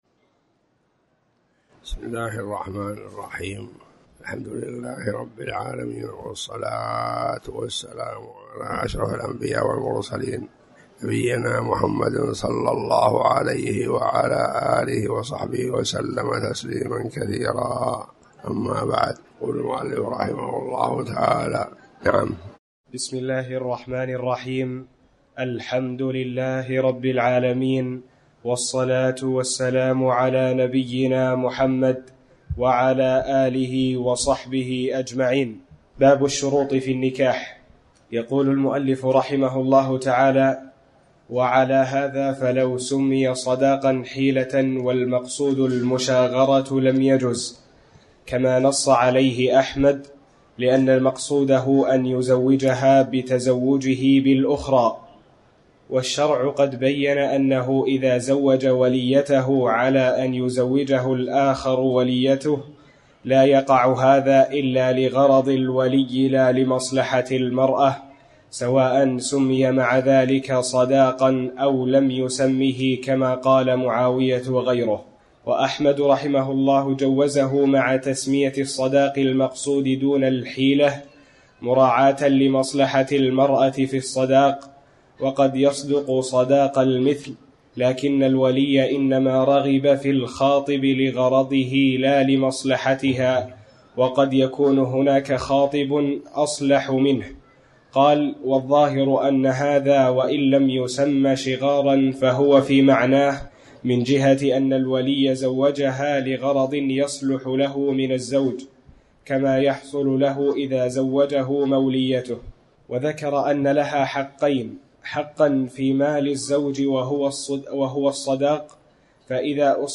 تاريخ النشر ٢٦ شوال ١٤٤٠ هـ المكان: المسجد الحرام الشيخ